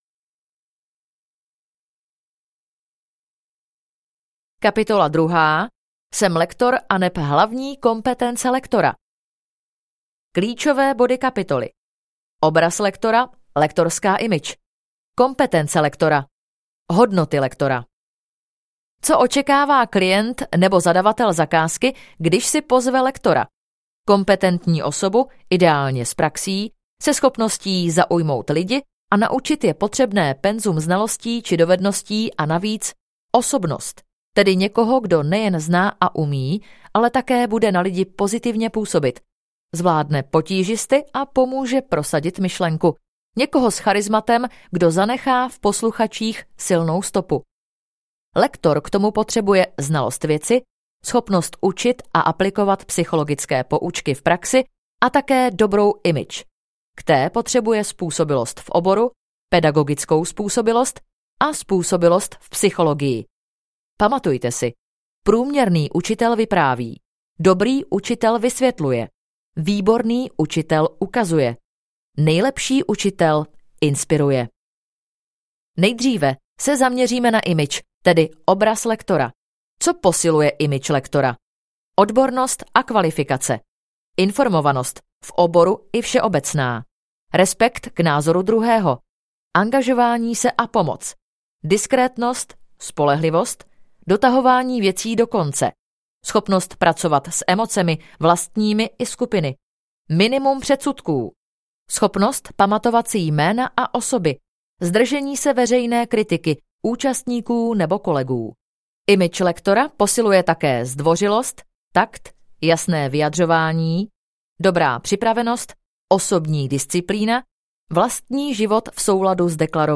Lektorské dovednosti audiokniha
Ukázka z knihy